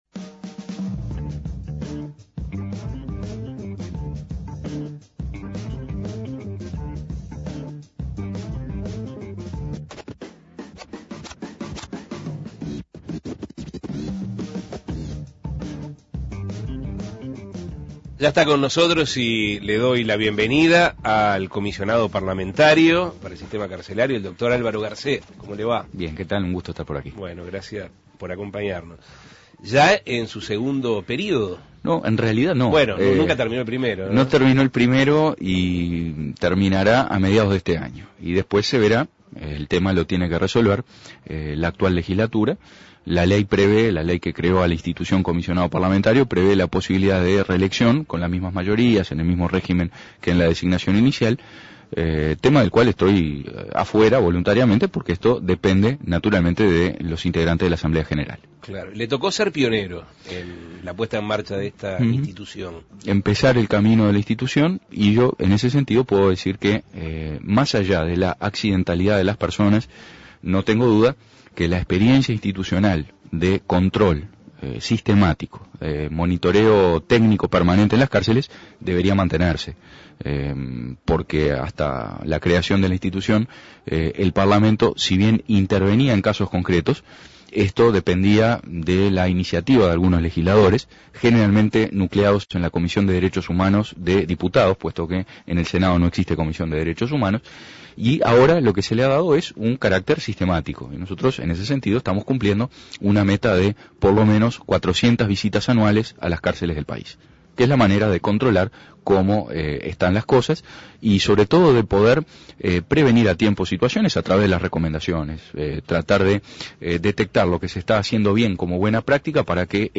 El comisionado parlamentario para el sistema carcelario, Álvaro Garcé, dialogó sobre la determinación planteada por el presidente José Mujica el pasado lunes, de permitir que las personas mayores de 70 años o que padezcan enfermedades de gravedad puedan cumplir su pena con arresto domiciliario, algo que está contemplado en la Ley del Procedimiento Penal y que deja la decisión final a criterio del juez. Escuche la entrevista.